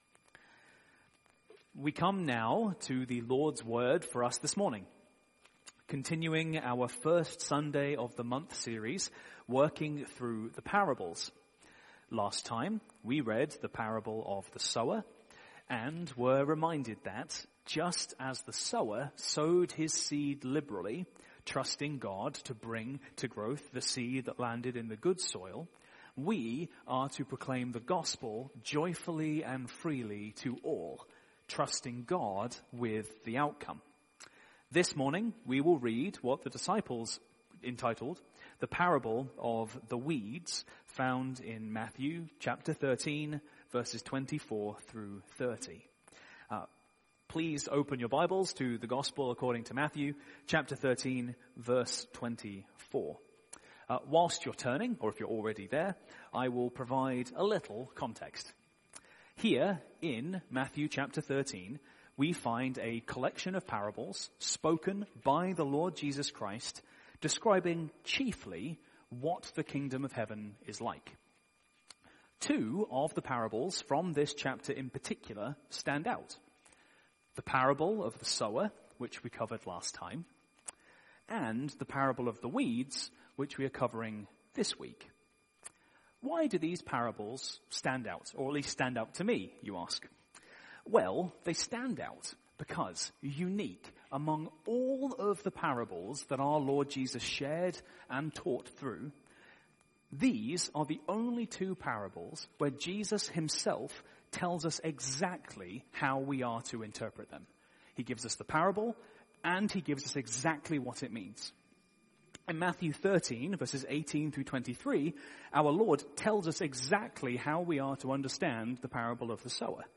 Sermon Series: Parables